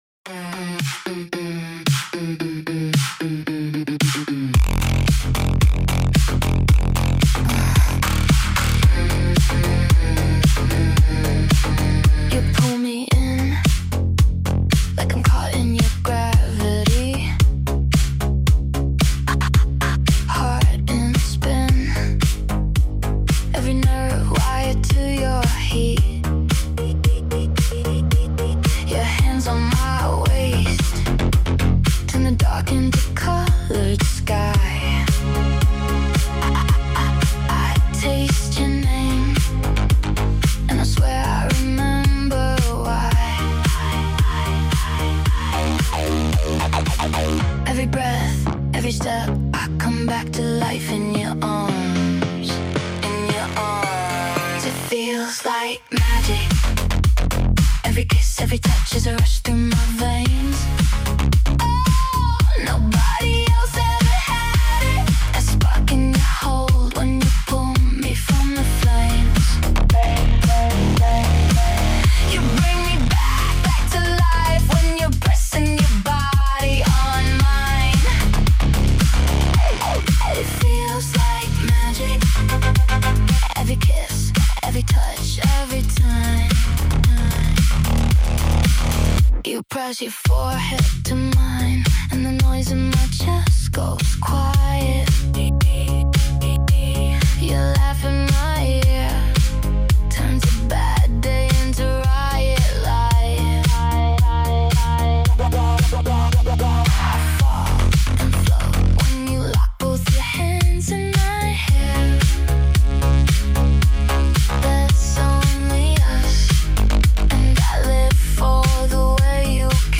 young girl singing about her feelings